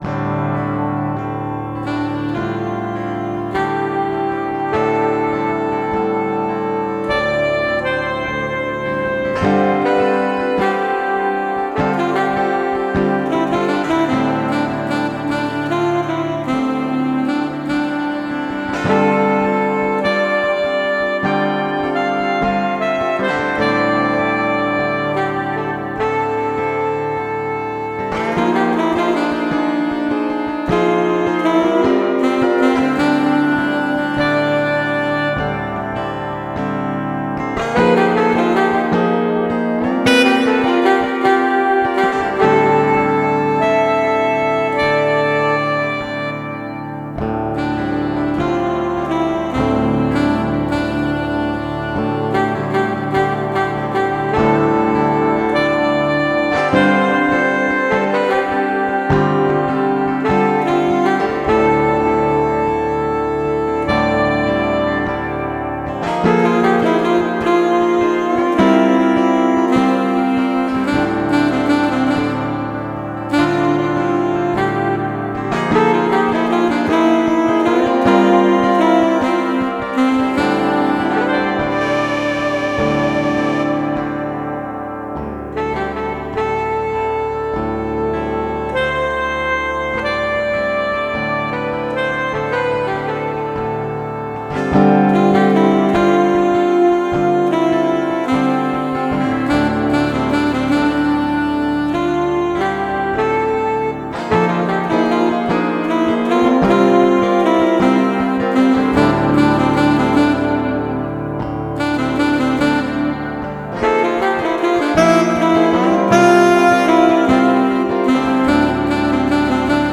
Jazzy Folk relaxed.